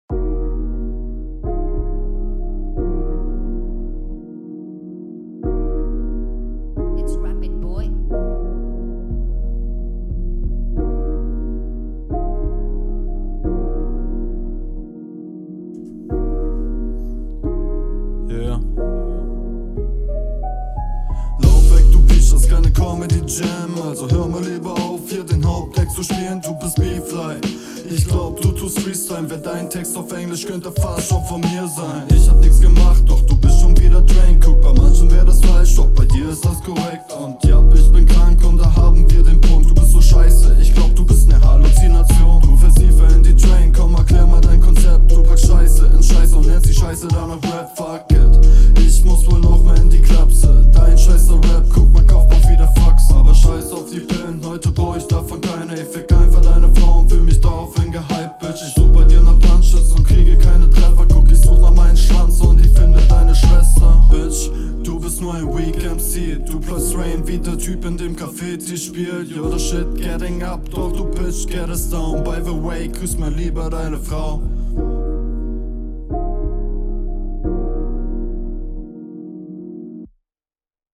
Der flow ist wiedermal extrem laid back und das …